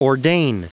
Prononciation du mot ordain en anglais (fichier audio)
Prononciation du mot : ordain